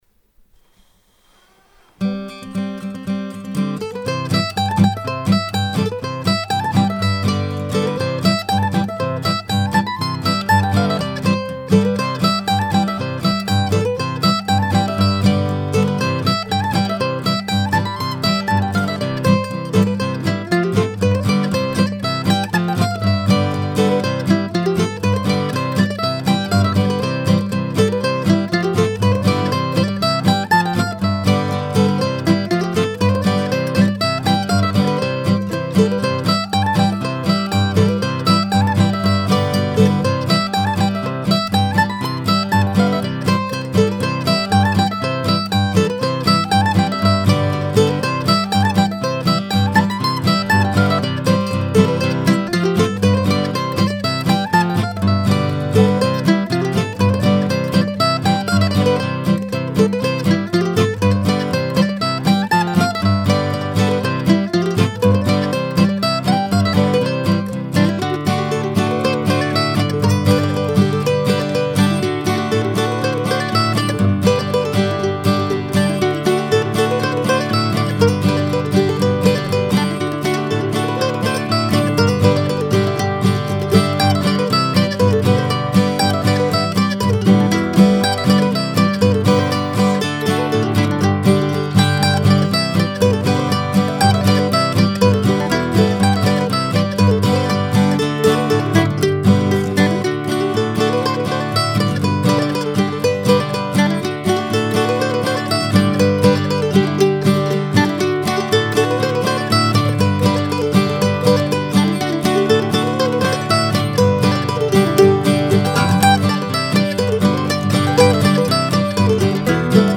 mp3 medley) (pdf)
Three tunes in a set that I recorded a couple of years ago for my bandmates in Contratopia.